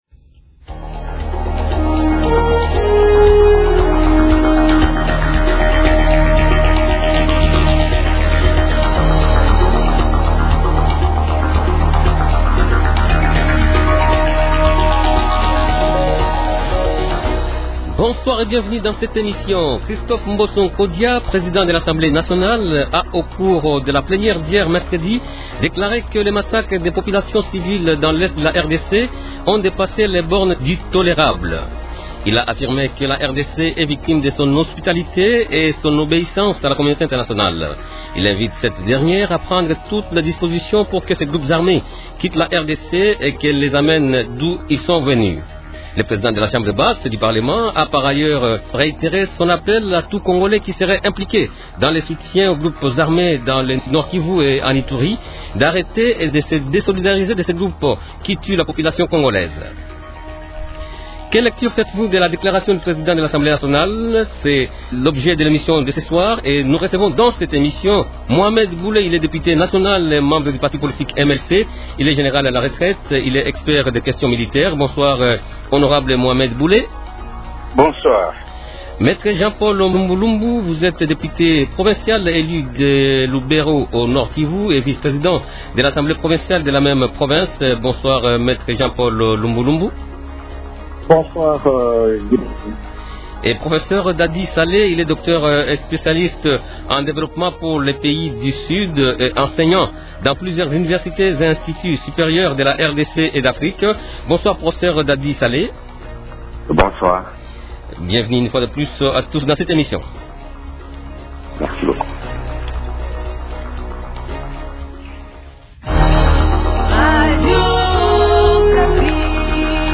Quelle lecture faites-vous de la déclaration du président de l’Assemblée nationale ? Invités : -Me Jean Paul Lumbu Lumbu, député provincial élu de Lubero au Nord-Kivu et vice-président de l’qssemblée provinciale de la même province. -Mohamed Bule, député national du MLC et général à la retraite.